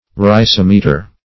\Ry*sim"e*ter\ (r[-i]*s[i^]m"[-e]*t[~e]r)